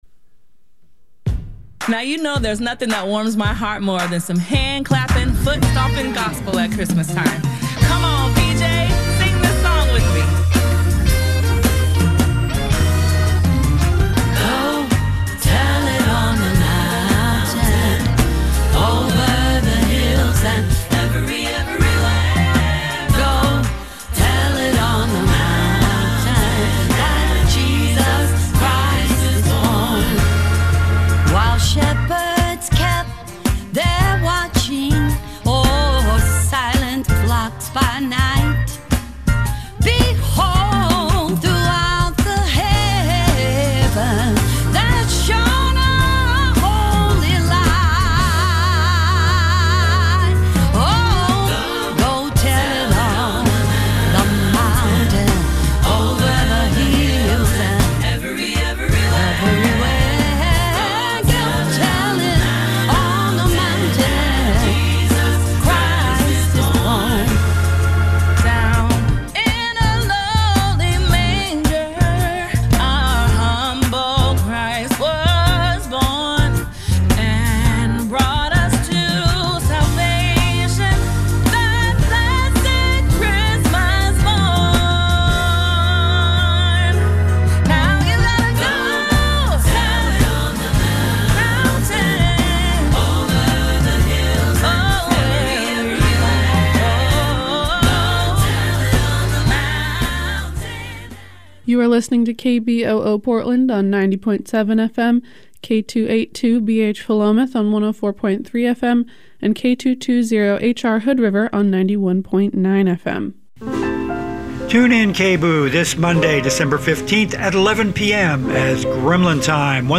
Conversations with leaders in personal and cultural transformation